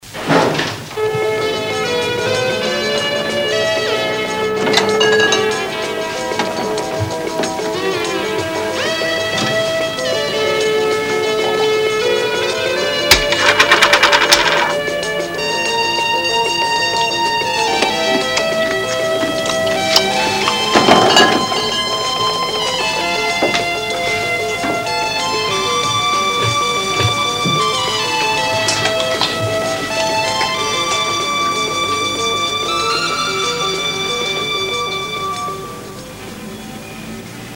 Mein Lieblingstonausschnitt aus einem Tatort überhaupt.
Der Tatverdächtige versteckt sich in einer abgedunkelten Wohnung, lässt sensationelle Synthesizermusik im Radio laufen, nimmt ein Glas aus dem Kühlschrank (Deckel rollt auf Oberfläche) und mampft eine Gurke